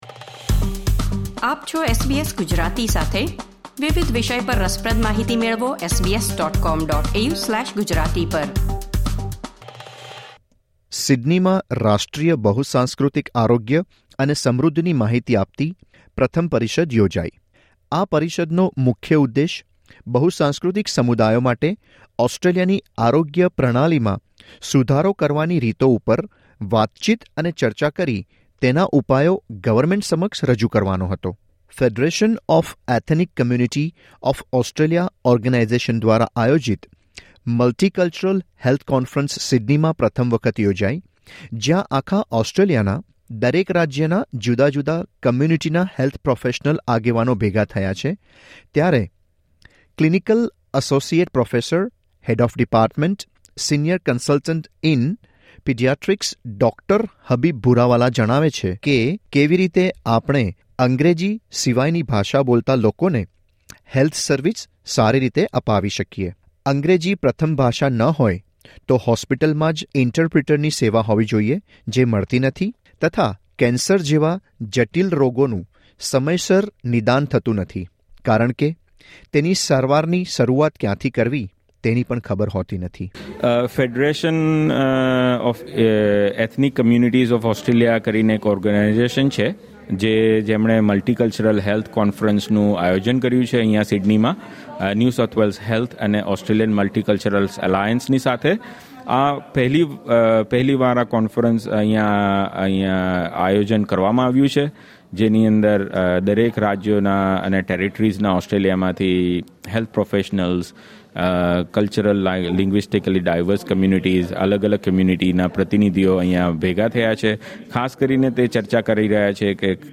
ઓસ્ટ્રેલિયામાં બહુસાંસ્કૃતિક સમુદાયને આરોગ્યલક્ષી સેવાઓ મેળવવામાં પડતી તકલીફ અને સેવાઓ વધુ યોગ્ય રીતે મળી રહે એ માટે સિડનીમાં એક કોન્ફરન્સનું આયોજન કરવામાં આવ્યું હતું. પરિષદમાં કેવી બાબતો પર ચર્ચા થઇ એ વિશેનો અહેવાલ.